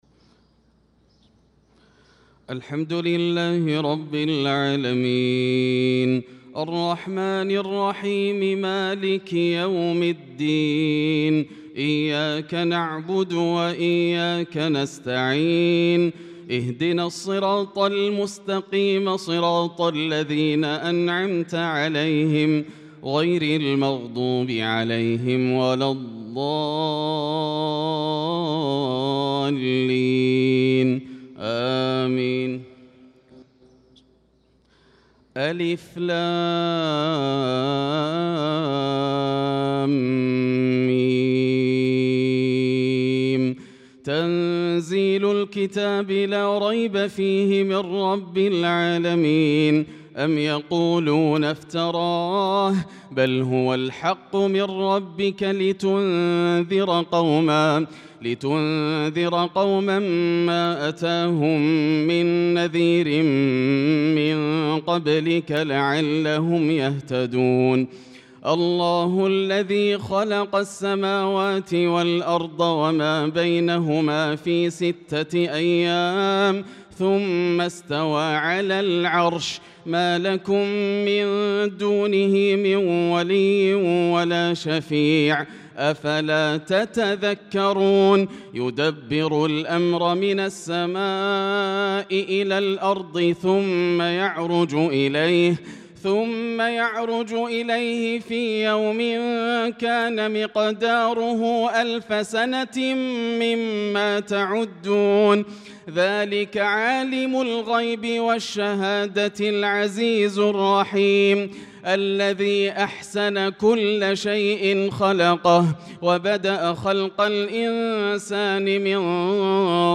صلاة الفجر للقارئ ياسر الدوسري 10 شوال 1445 هـ